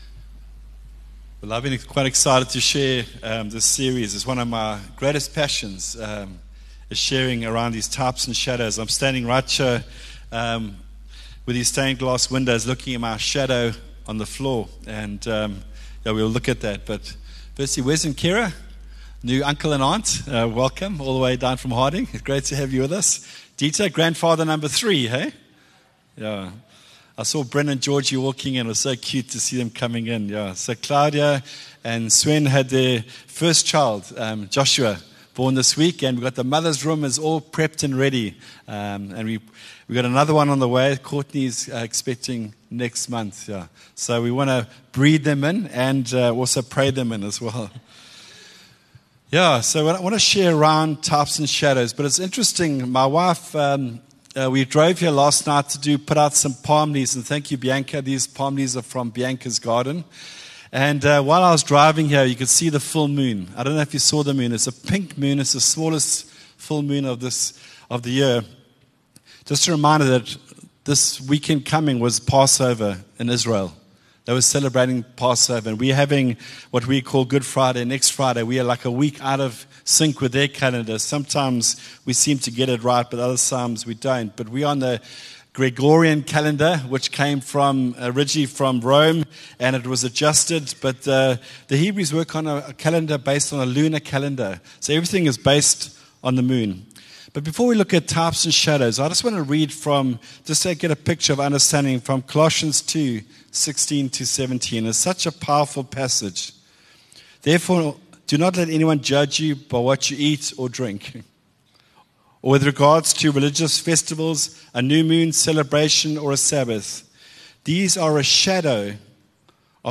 Palm Sunday.
View Promo Continue JacPod Install Upper Highway Vineyard Sunday messages 13 Apr Types & Shadows 22 MIN Download